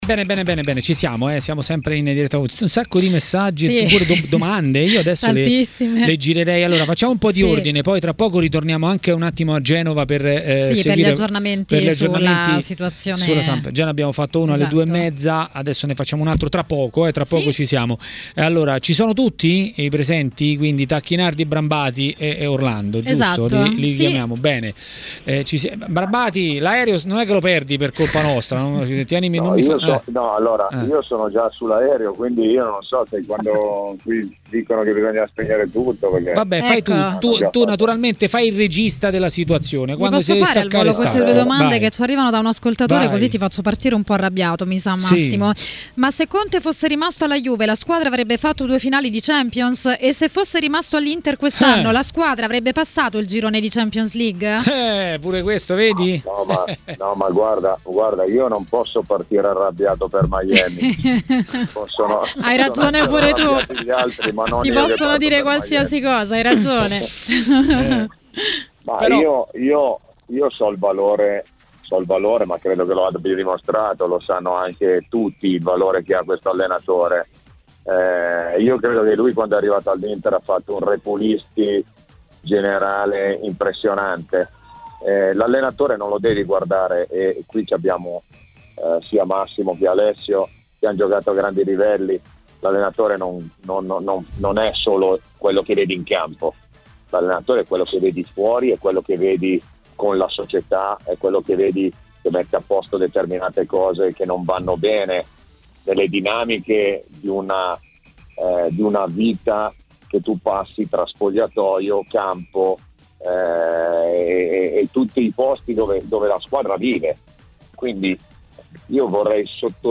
L'ex calciatore Massimo Orlando a TMW Radio, durante Maracanà, ha parlato di Milan e non solo.